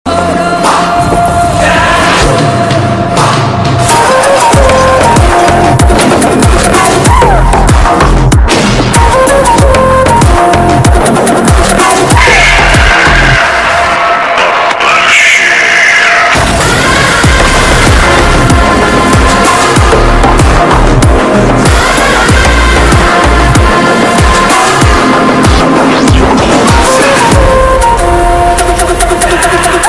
without vocal